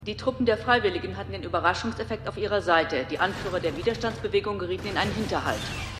MissionErde_3x06_Sprecherin.mp3